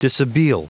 Prononciation du mot dishabille en anglais (fichier audio)
Vous êtes ici : Cours d'anglais > Outils | Audio/Vidéo > Lire un mot à haute voix > Lire le mot dishabille